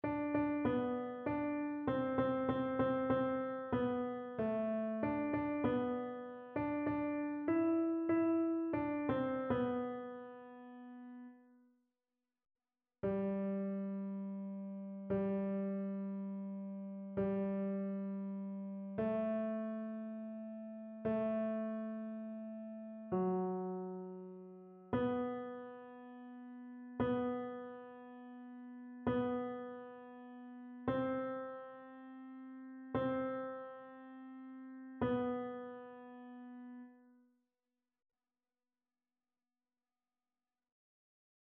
annee-a-temps-ordinaire-20e-dimanche-psaume-66-tenor.mp3